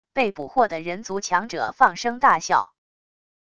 被捕获的人族强者放声大笑wav音频